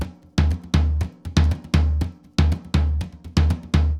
Surdo 1_Salsa 120_1.wav